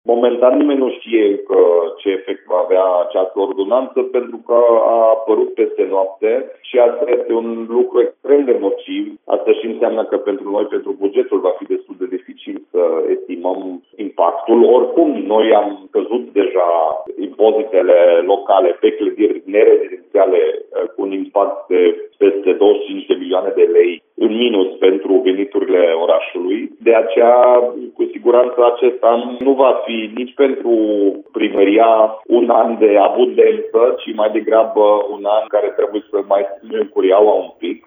Primarul Dominic Fritz a declarat, pentru Radio Timișoara, că nu se cunoaște încă ce impact vor avea avea asupa bugetului local.